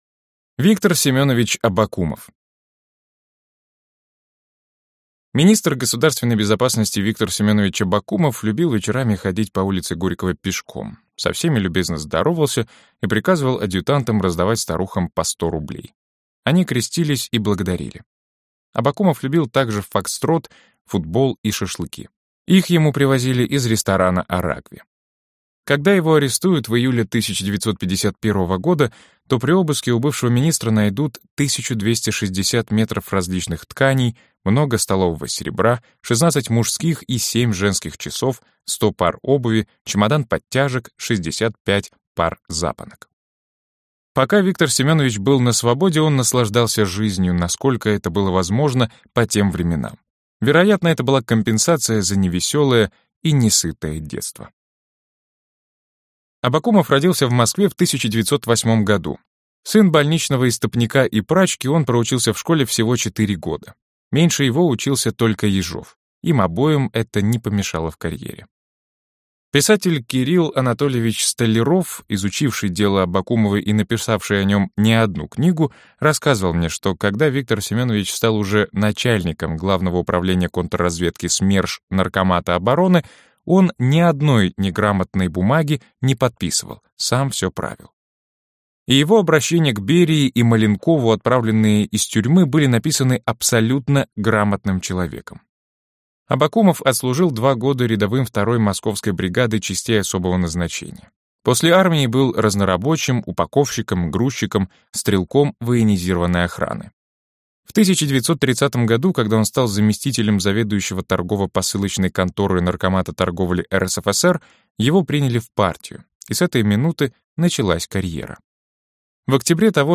Аудиокнига Машина большого террора. Сороковые и пятидесятые | Библиотека аудиокниг